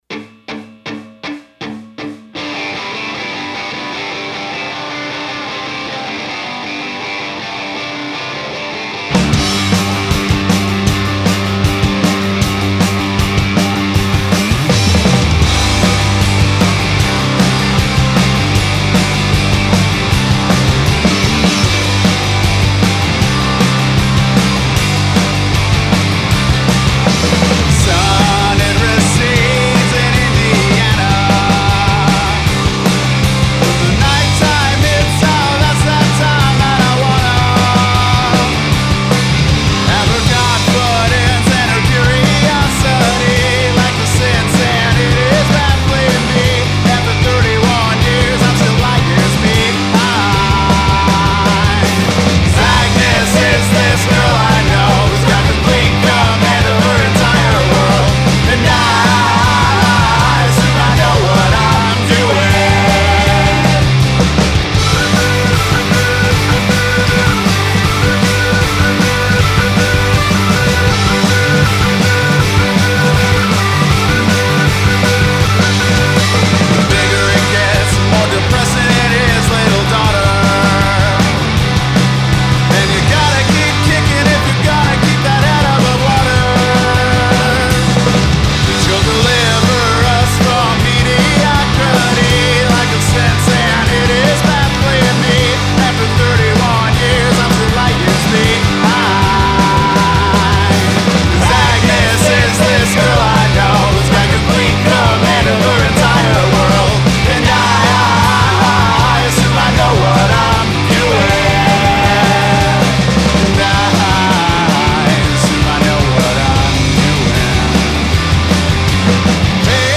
Guitars, Vocals, Keys
Drums
Bass
Cello
Trombone